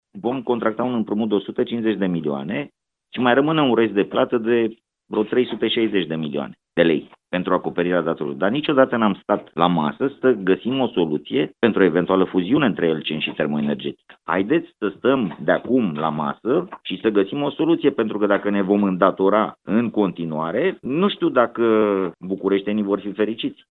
Consilierul general Ovidiu Zară, de la AUR, a insistat să înceapă negocierile pentru fuziunea Elcen–Termoenergetica, astfel încât primăria să nu se mai împrumute anual pentru plata facturilor.